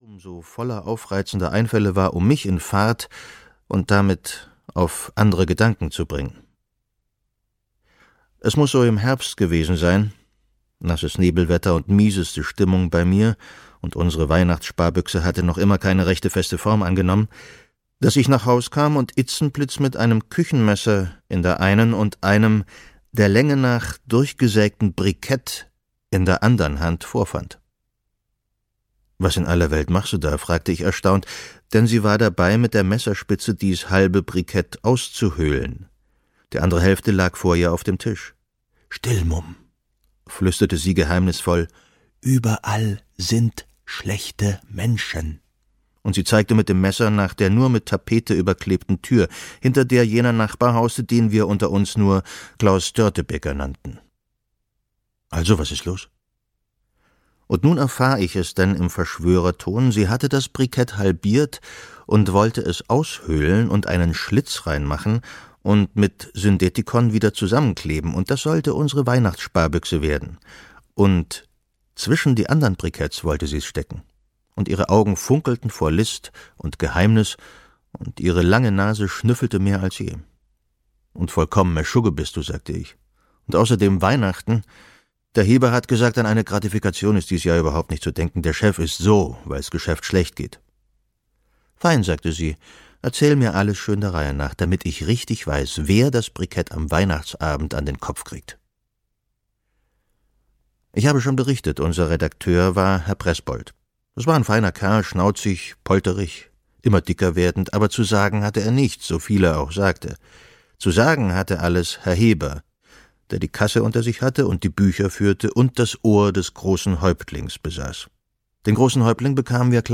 Christkind verkehrt - Hans Fallada - Hörbuch